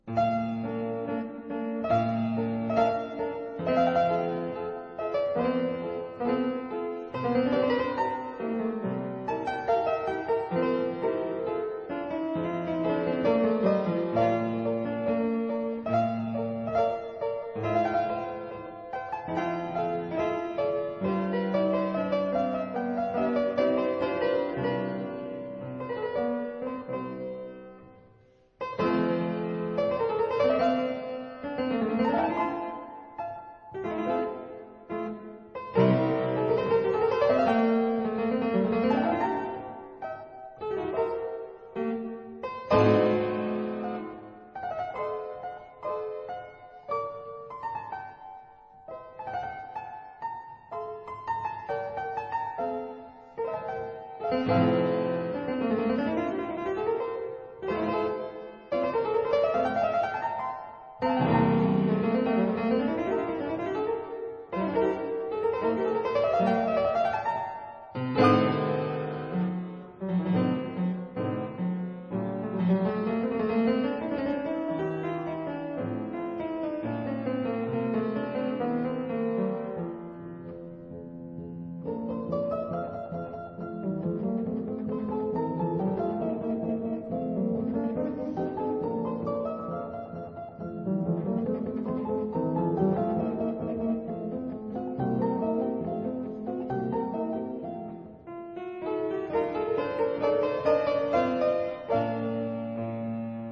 四手聯彈，如影隨形
這是在古鋼琴上（Pianoforte）演奏的四手聯彈。
四手聯彈在鋼琴上的變化，非常豐富。
第二雙手則是如影隨形，或重複、或疊聲、或陪伴哼唱。